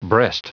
Prononciation du mot breast en anglais (fichier audio)
Prononciation du mot : breast